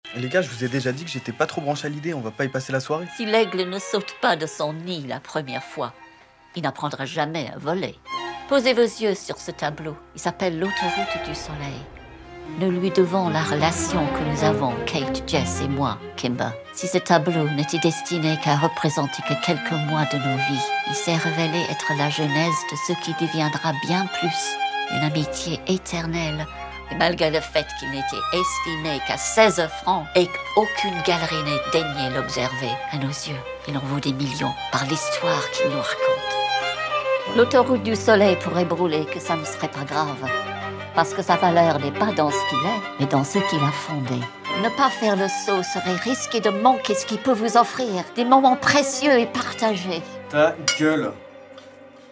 Voix off
- Soprano